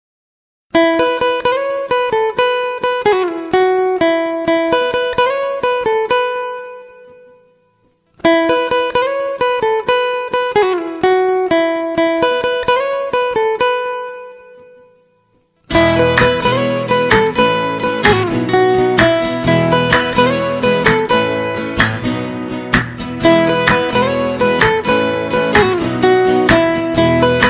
• Качество: 128, Stereo
гитара
спокойные
без слов
красивая мелодия
инструментальные
романтические